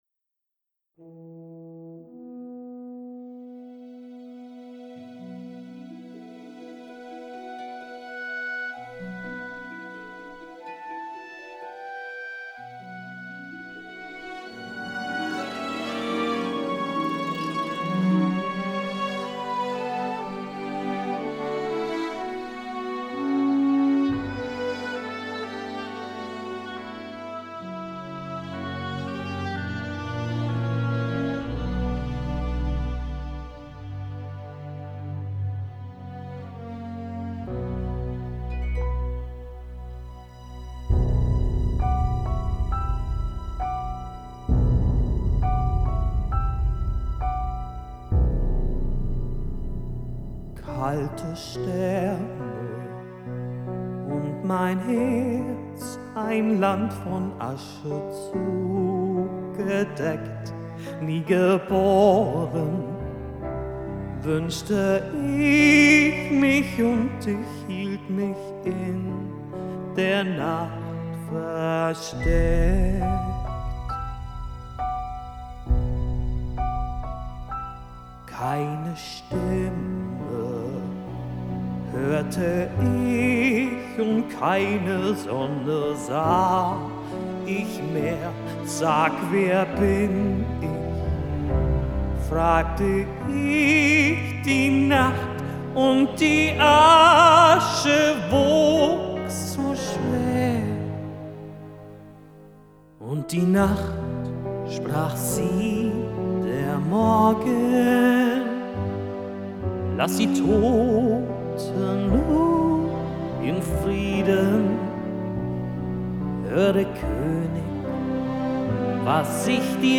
Musical: